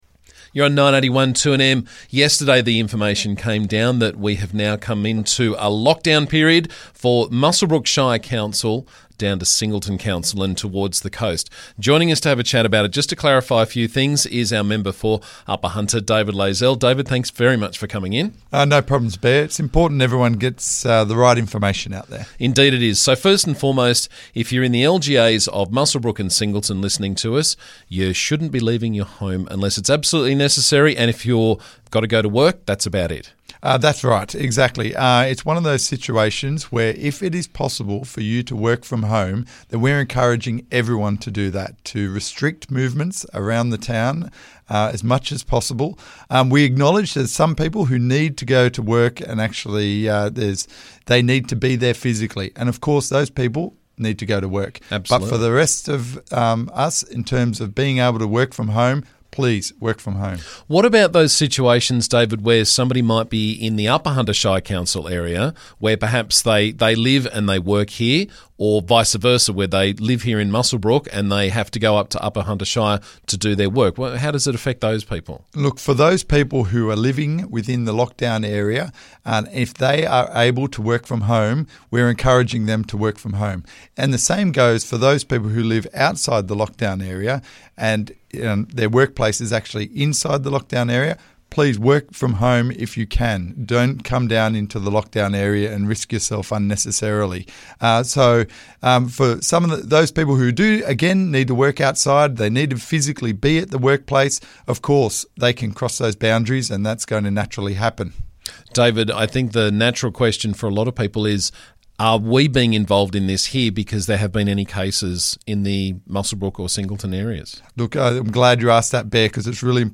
With the 7-day lockdown in place I spoke with David this morning about how this will effect residents of the Upper Hunter Shire LGA.